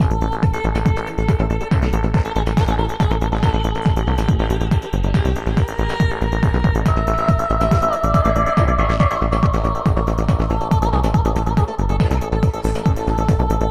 Gated Vocal effects are a cool way of adding extra rhythmic variations and dynamics to you tunes.
Since, delay effects are also appropriate for this kind of thing, here is another sample with some delay added as well.